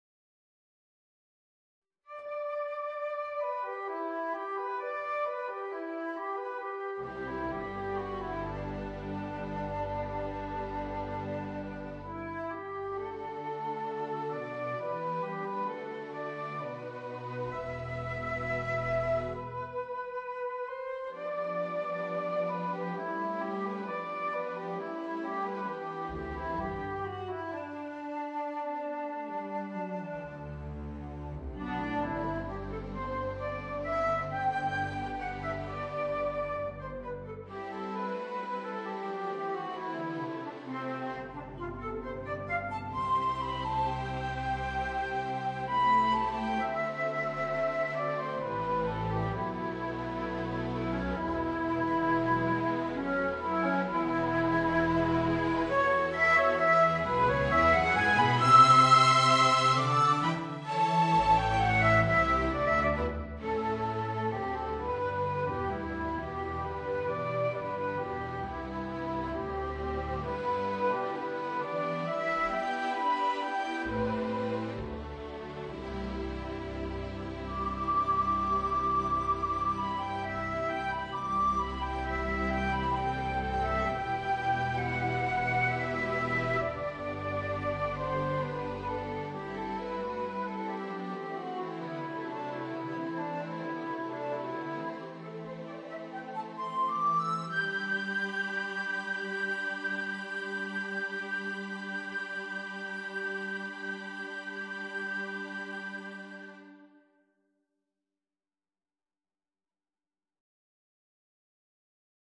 Voicing: Flute and String Quintet